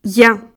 lettre-ya.ogg